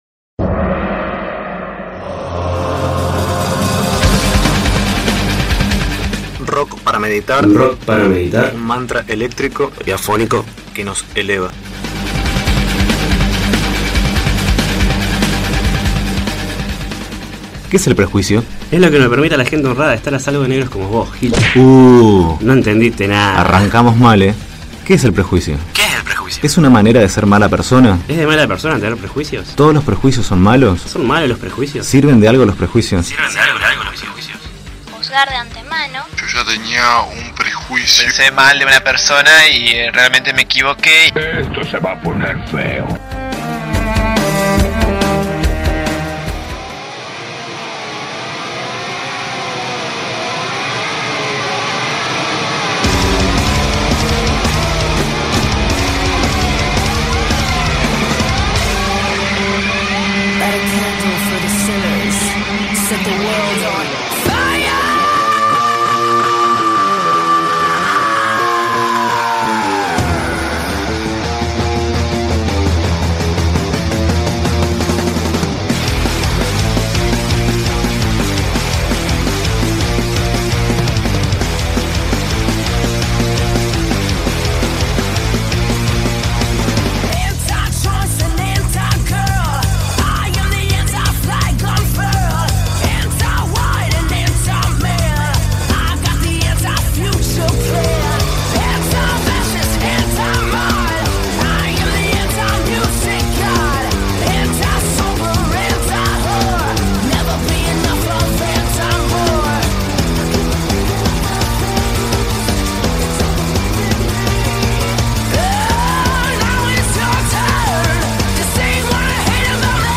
Segundo programa de "Rock para meditar", microdosis de buena música y flasheos sobre el rock.